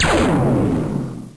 ray_gun.wav